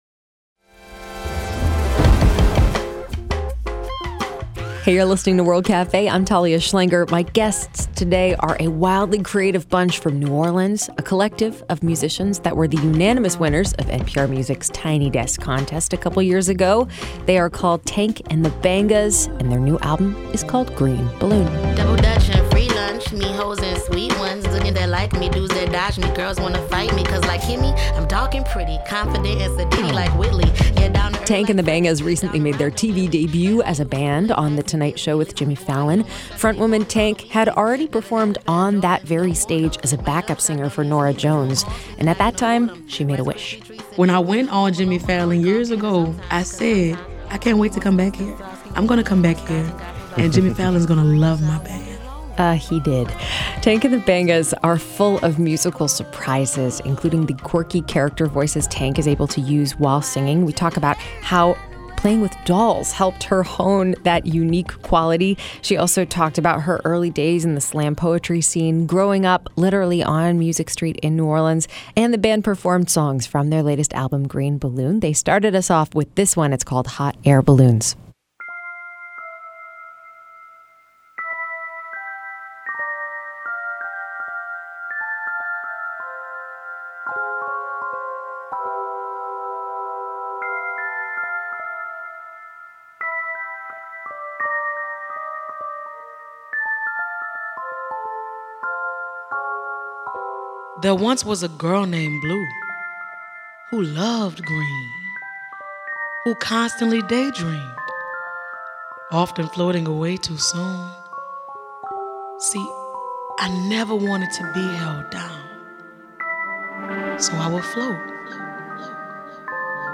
Lead singer Tarriona "Tank" Ball talks about how she honed the unique roster of character voices she uses in her music.
2019 Share Tank and the Bangas inside the World Cafe Performance Studio